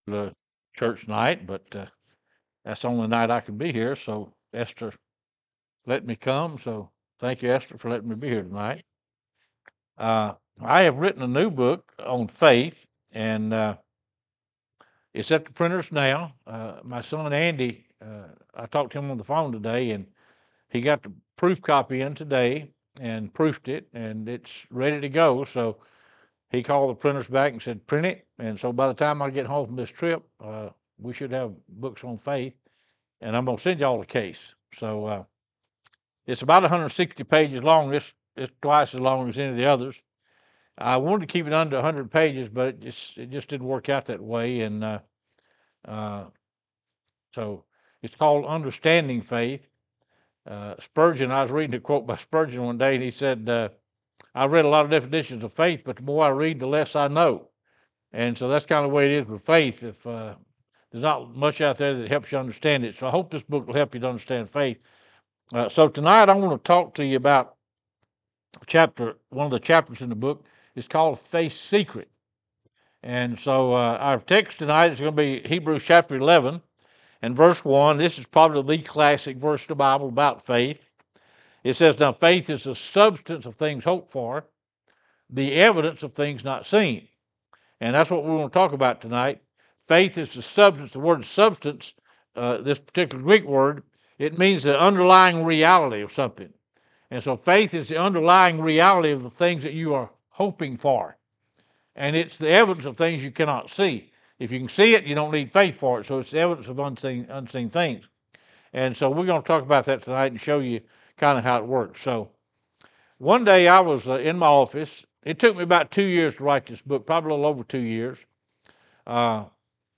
Hebrews 11:1 Service Type: Teachings There are secrets of faith that some of us have never heard before